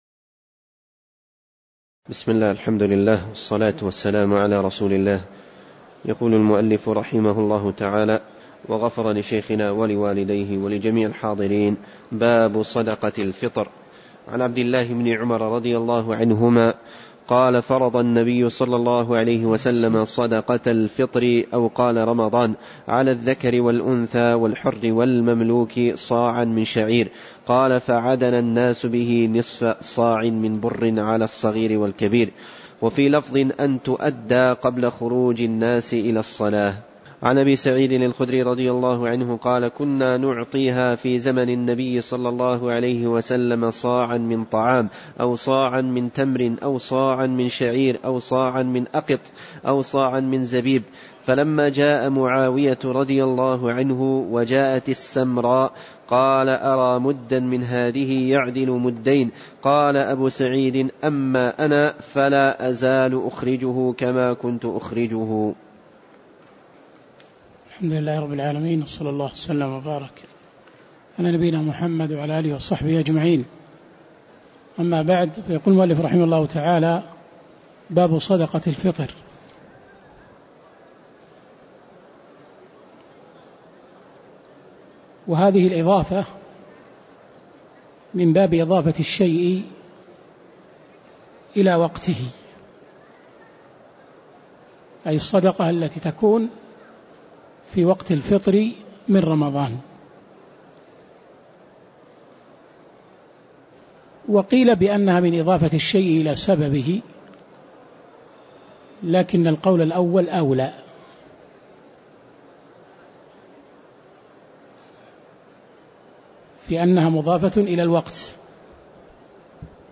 تاريخ النشر ١٣ جمادى الآخرة ١٤٣٨ هـ المكان: المسجد النبوي الشيخ